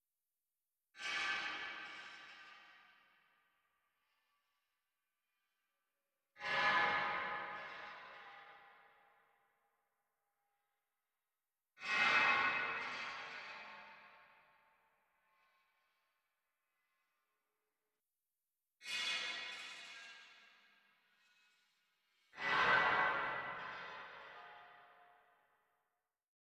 0335ec69c6 Divergent / mods / Soundscape Overhaul / gamedata / sounds / ambient / soundscape / underground / under_26.ogg 638 KiB (Stored with Git LFS) Raw History